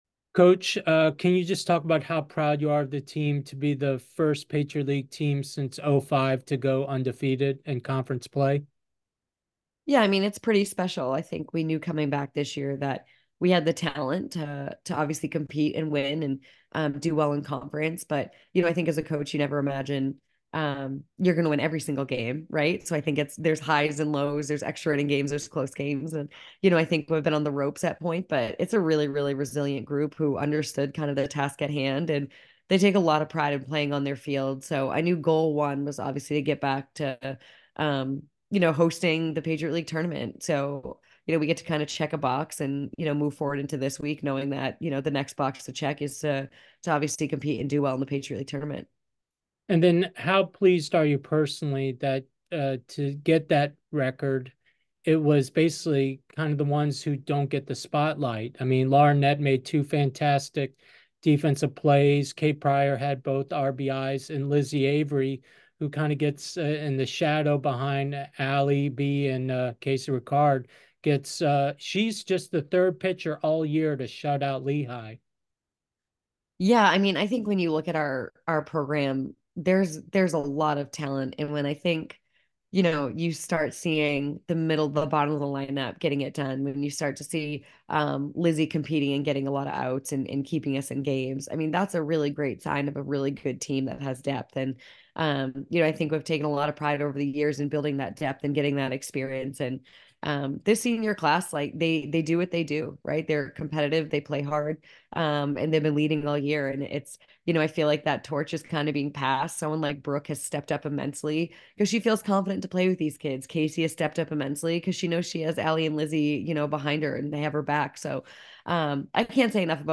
Lehigh Postgame Interview